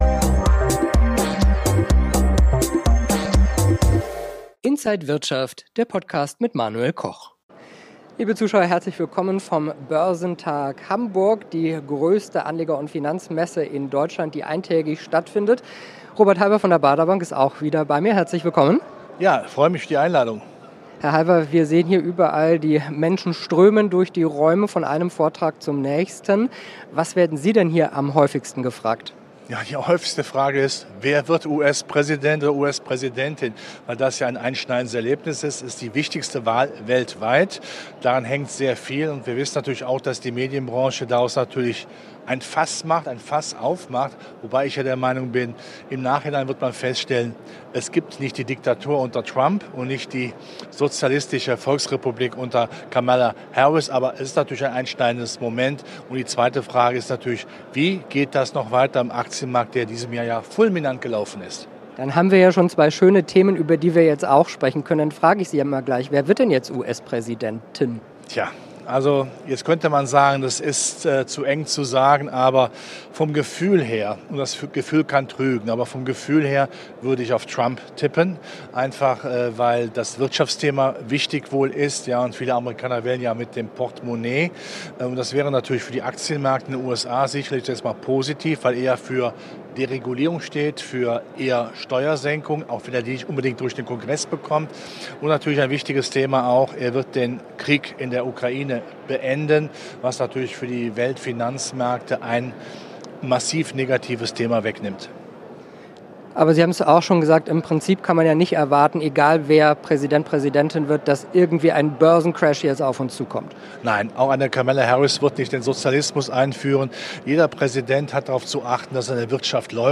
Alle Details im Interview von Inside
auf dem Börsentag Hamburg